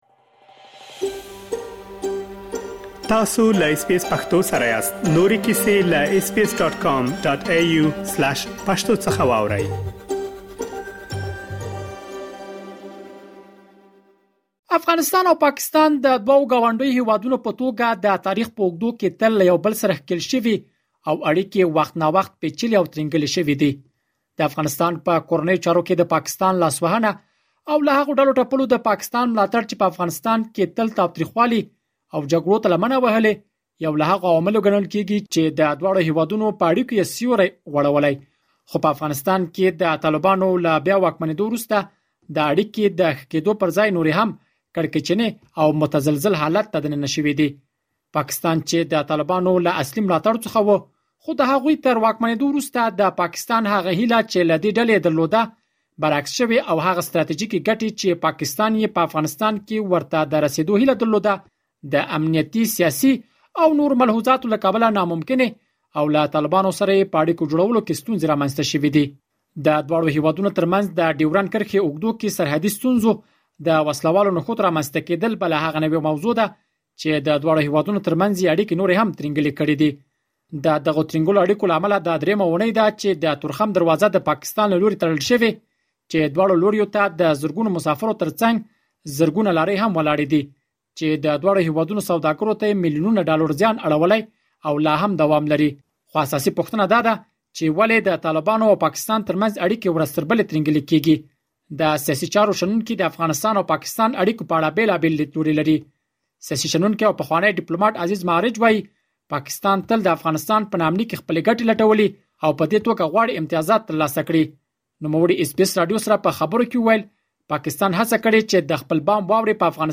مهرباني وکړئ لا ډېر معلومات په رپوټ کې واورئ.